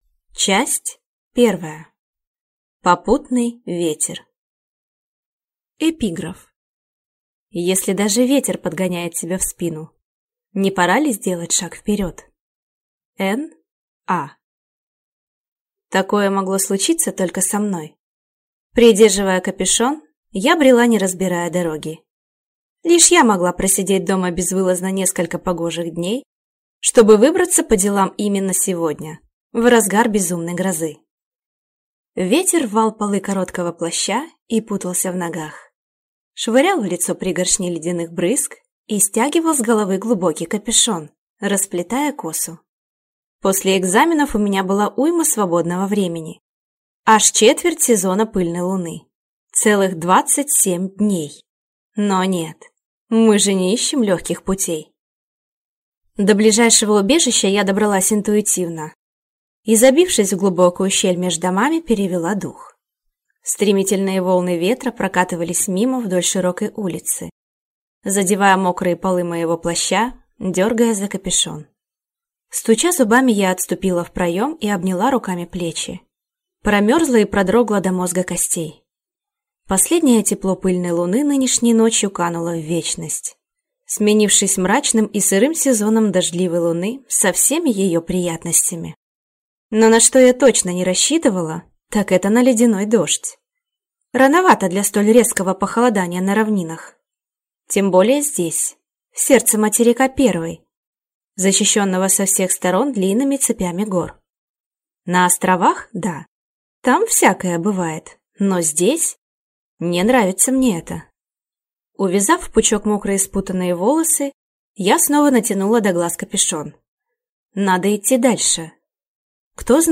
Аудиокнига Дух ветра | Библиотека аудиокниг